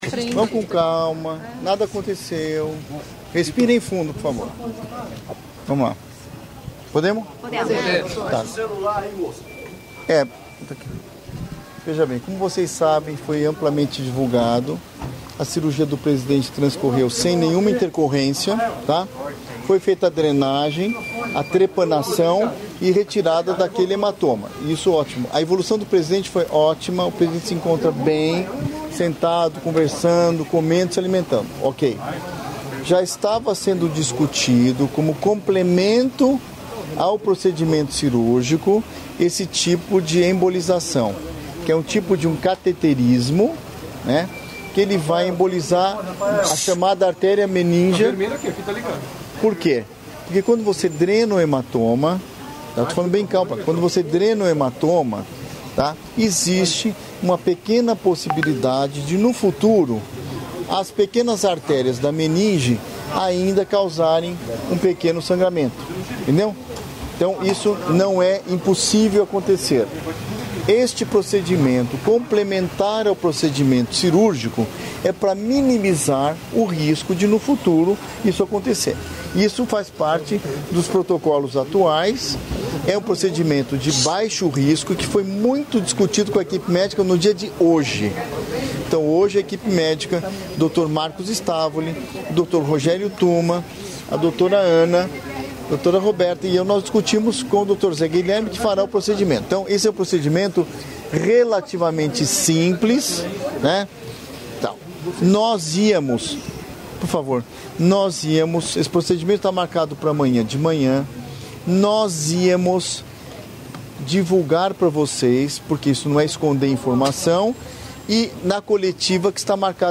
Íntegra da coletiva
realizada nesta quinta-feira (12), no Hospital Sírio-Libanês, em São Paulo